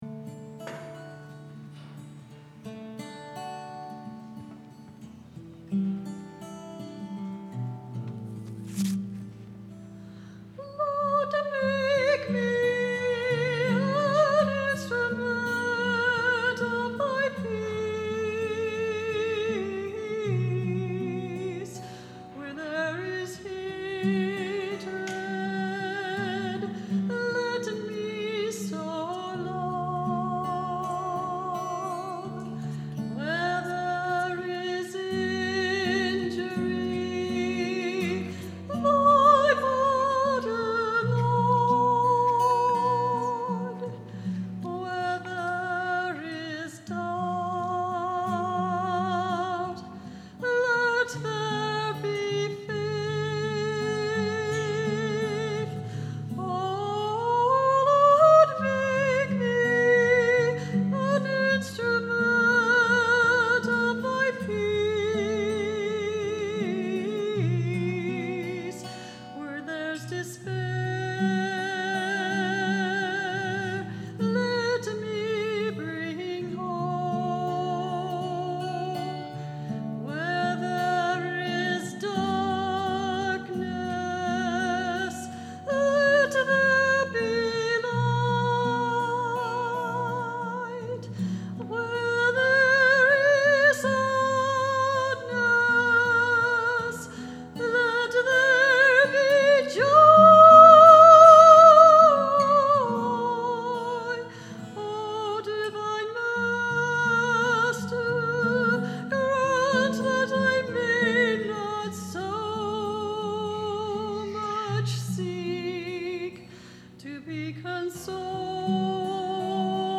during offering